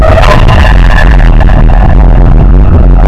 Banana_thump_audio.mp3